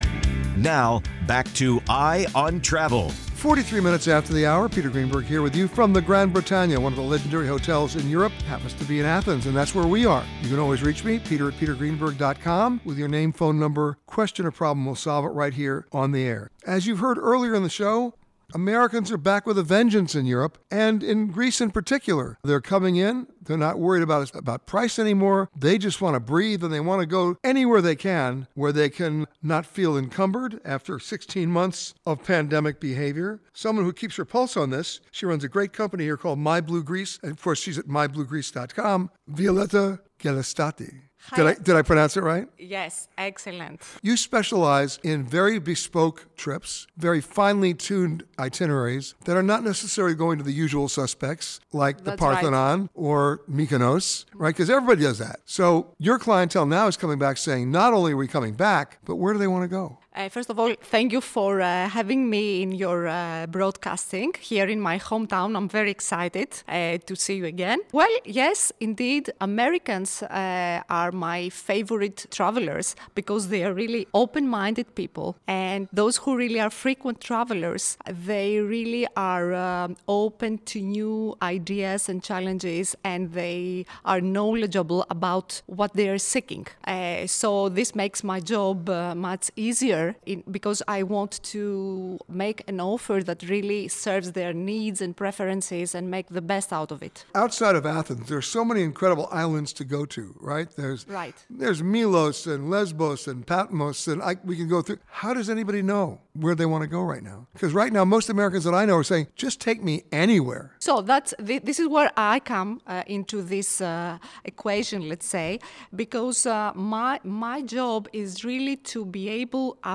EYE ON TRAVEL-Hotel Grande Bretagne in Athens, Greece